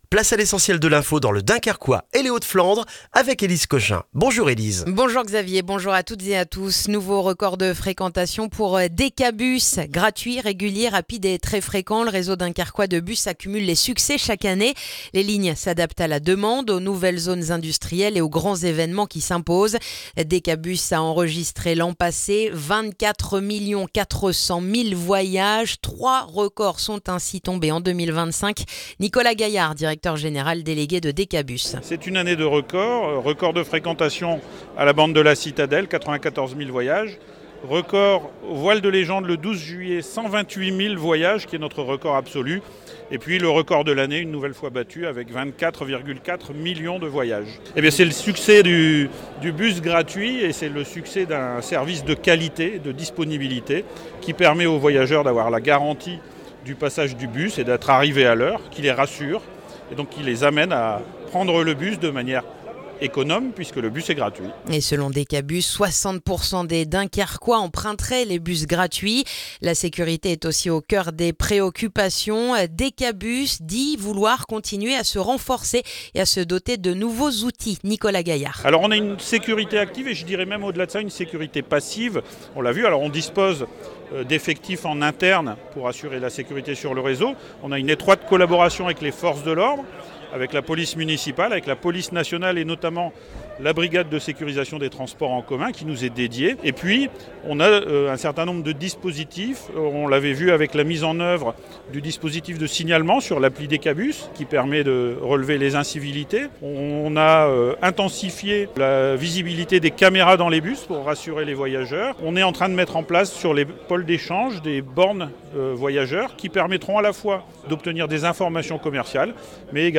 Le journal du vendredi 23 janvier dans le dunkerquois